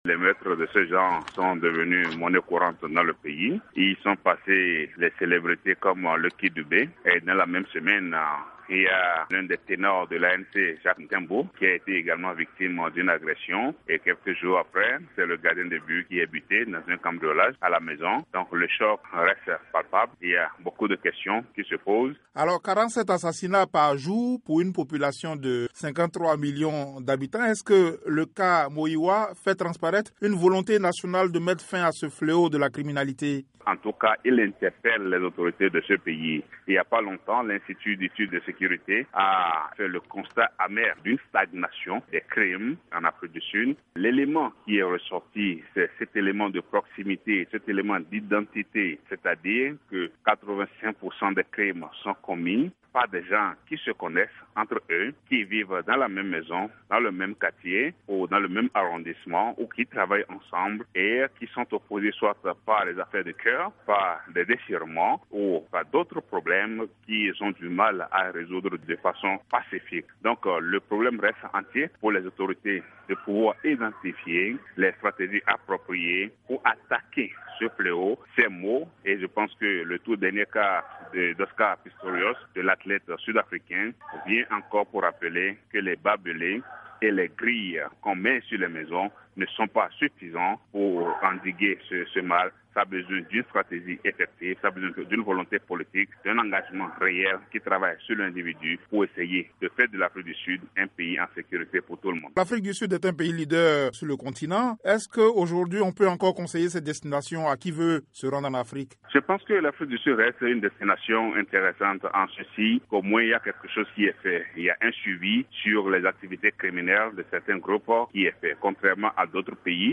interviewé